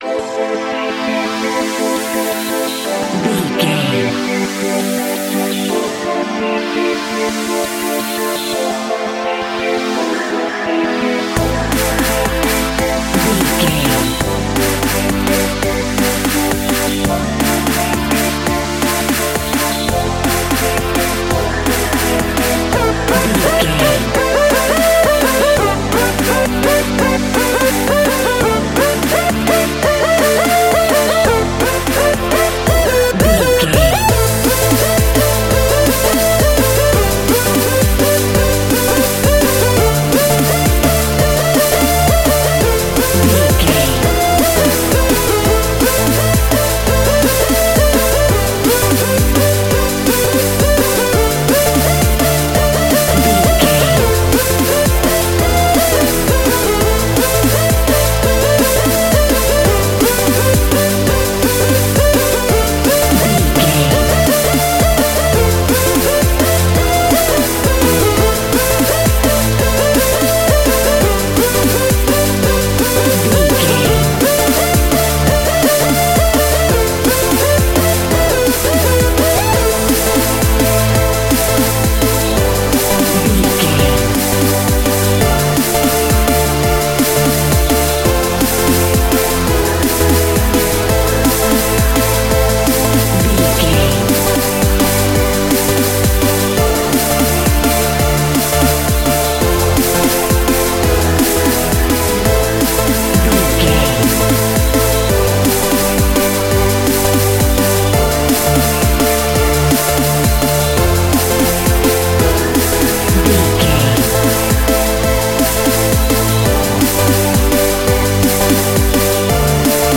Club Drum n Bass.
Fast paced
Aeolian/Minor
aggressive
dark
driving
energetic
groovy
drum machine
synthesiser
electronic
sub bass
synth leads
synth bass